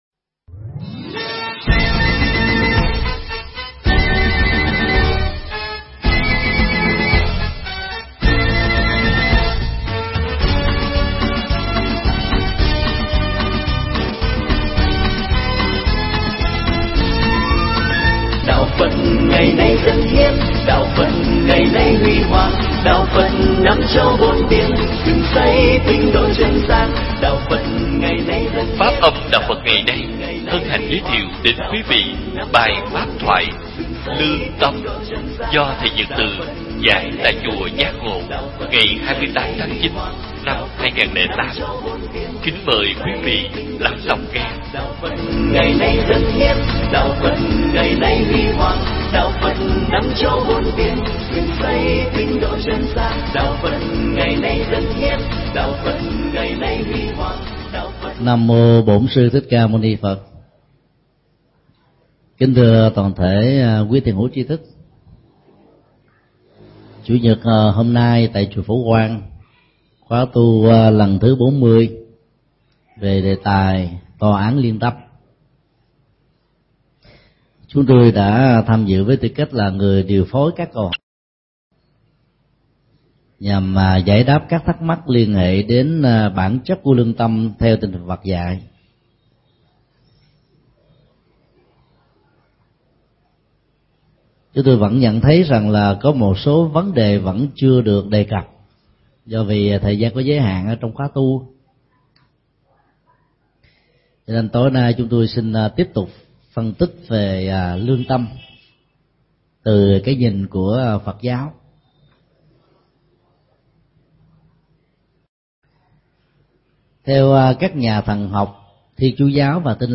Nghe mp3 thuyết pháp Lương tâm do thầy Thích Nhật Từ giảng tại Chùa Giác Ngộ, ngày 28 tháng 09 năm 2008.